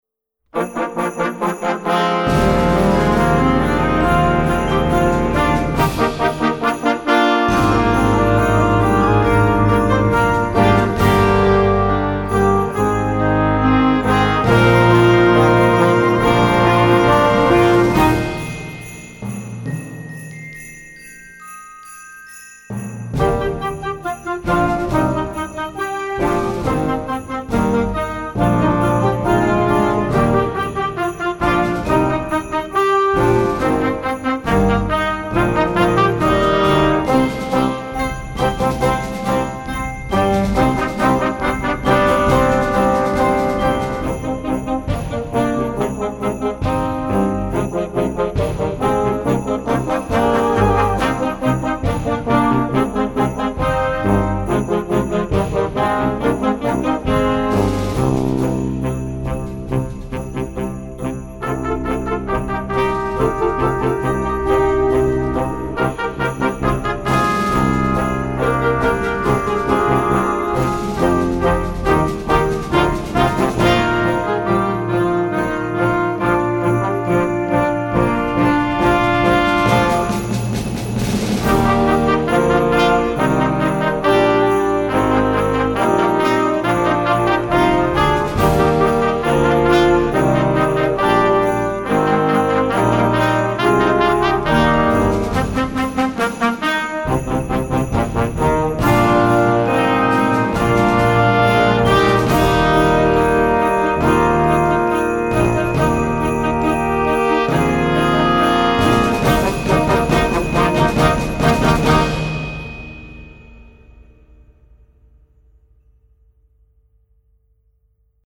wonderful new holiday chart